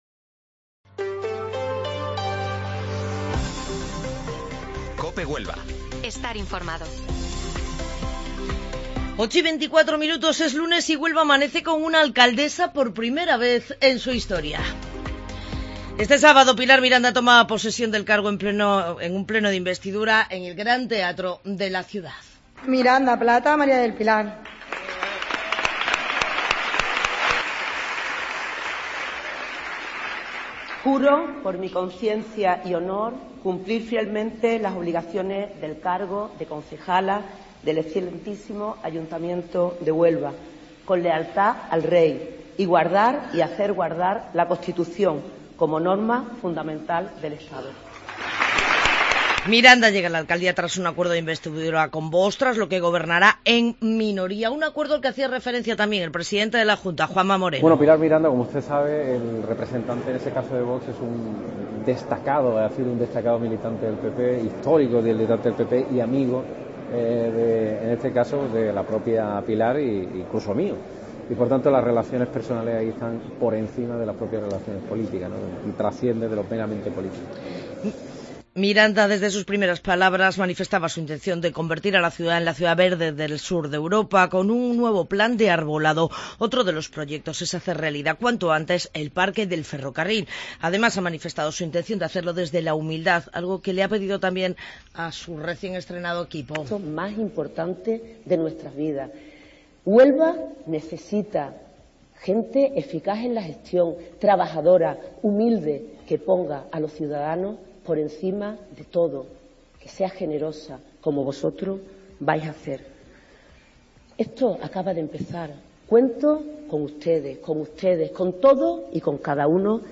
Informativo Matinal Herrera en Cope 19 de junio